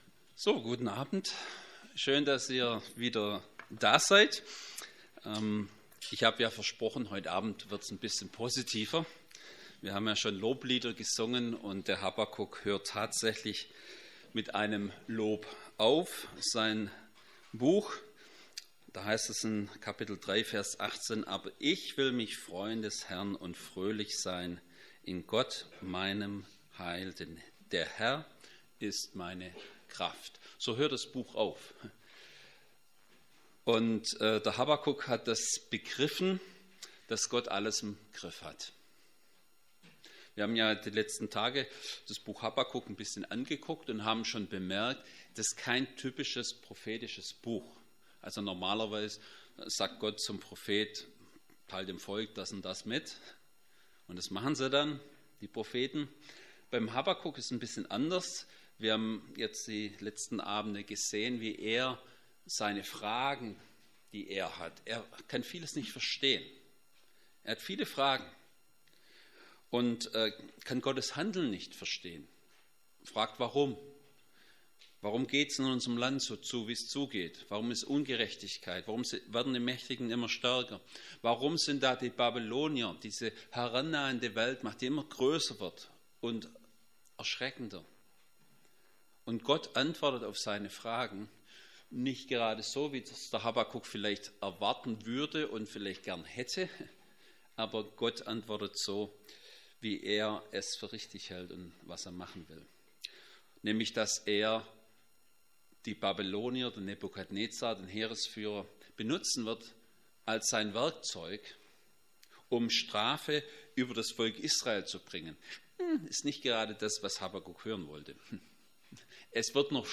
Habakuk 3,1-19 Predigt.mp3